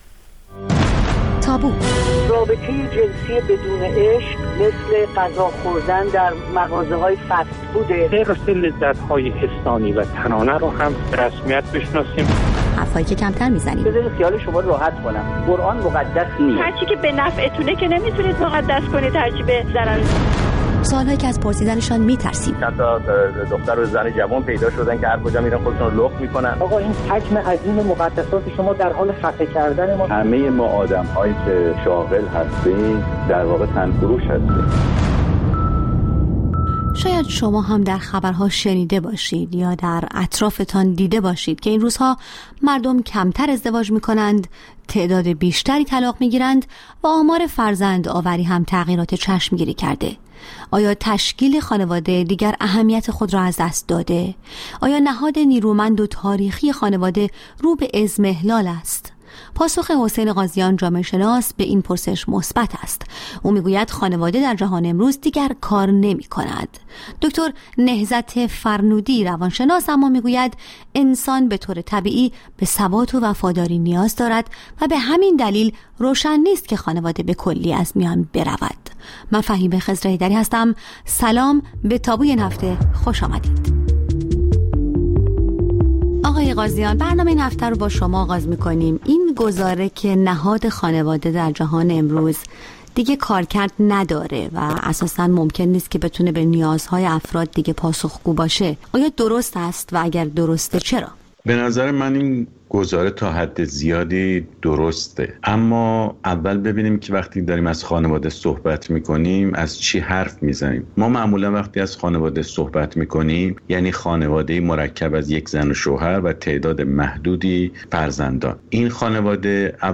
با دو‌ مهمانِ برنامه درباره‌ی موضوعاتی که اغلب کمتر درباره‌شان بحث و گفت‌وگو کرده‌ایم به مناظره می‌نشیند. موضوعاتی که کمتر از آن سخن می‌گوییم یا گاه حتی ممکن است از طرح کردن‌شان هراس داشته باشیم.